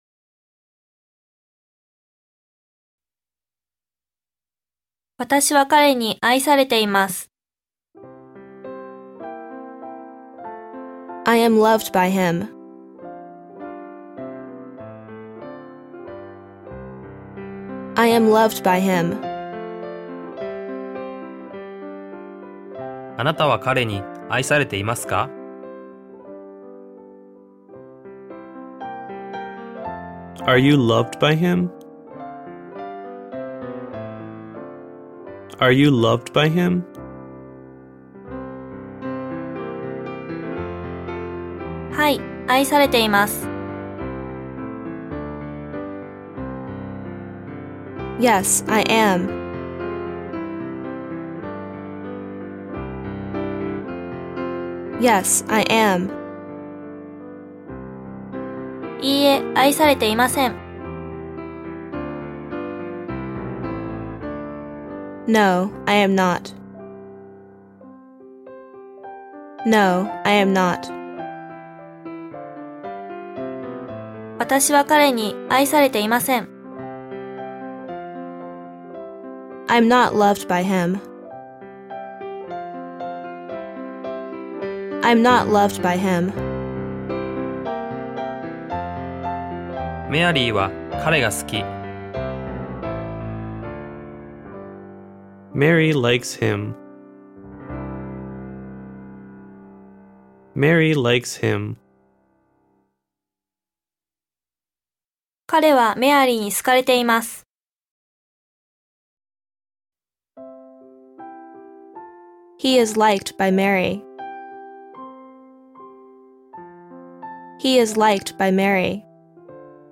日本語ナレーションから考えた後、ネイティブ英語を聞く・まねる・話すアウトプットを通して英語が深く定着。BGMに乗ってまねて話せすので、ネイティブのような発音が自然と身につきます。
※英語・ピアノBGMとも高品質録音につき、bluetoothで聞くと、ネイティブ発音も非常にクリアー！